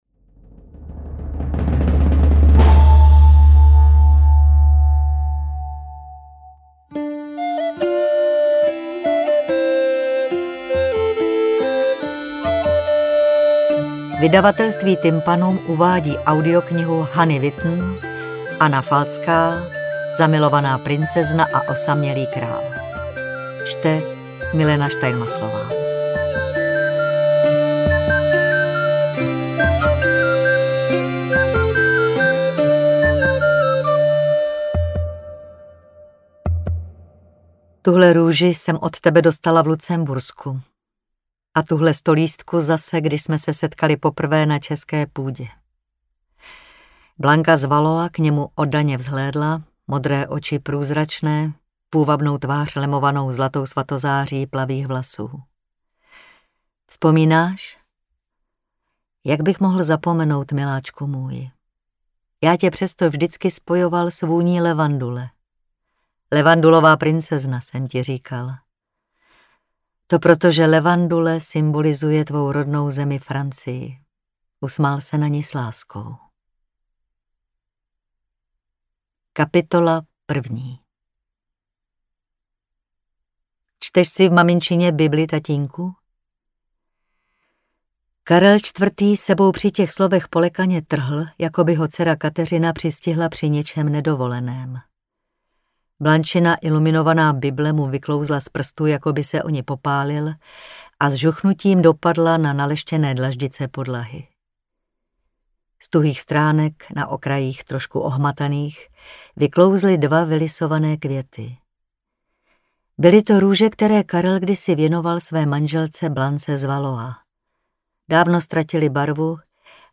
Interpret:  Milena Steinmasslová